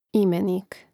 ȉmenīk imenik